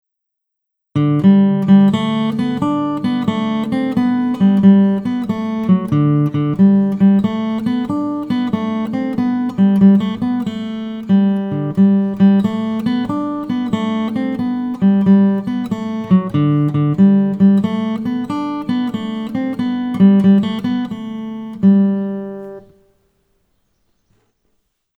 Guitar Solo
DIGITAL SHEET MUSIC - FINGERPICKING SOLO